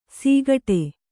♪ sīgaṭe